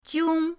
jiong1.mp3